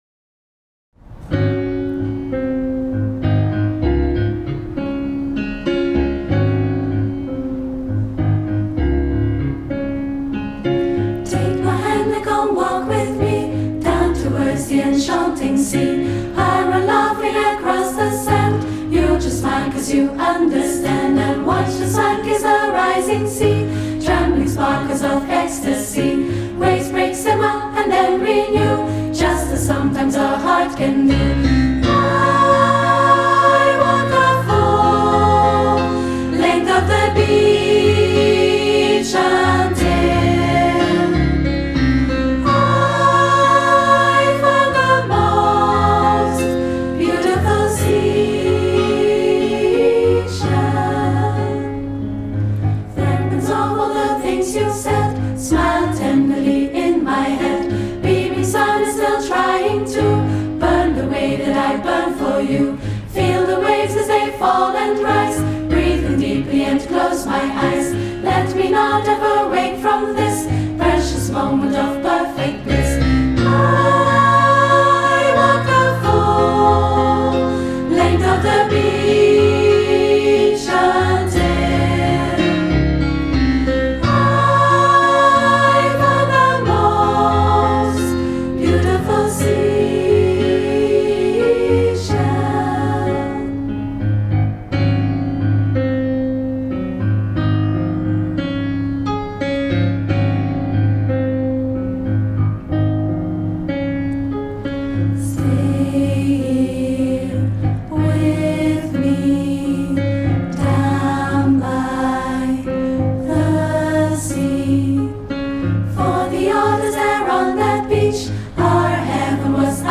girls choir
intimate setting